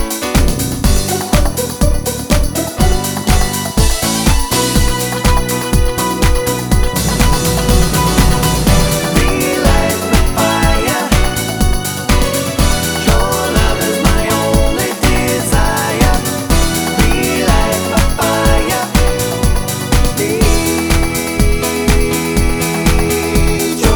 With Female Lead Pop (1990s) 3:59 Buy £1.50